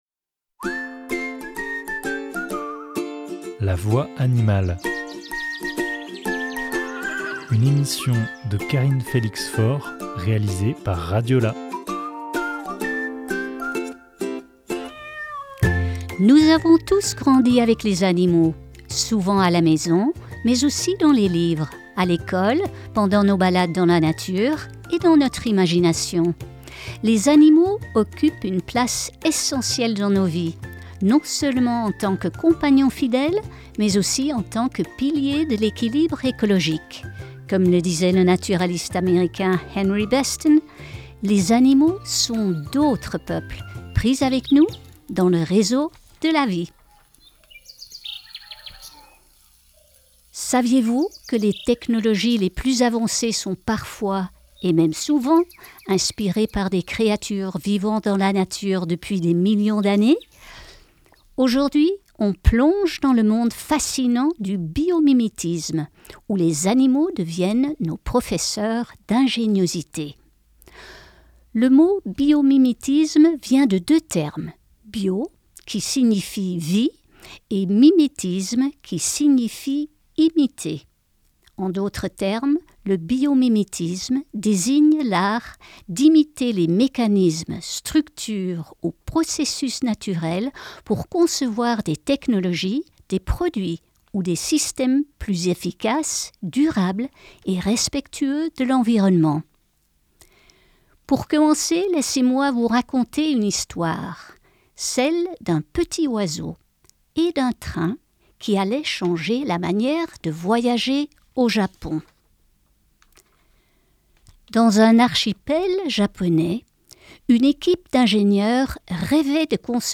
Découvrez chaque mois une espèce ou un concept animalier, à travers des anecdotes, des lectures, des interviews d’experts et des choix musicaux.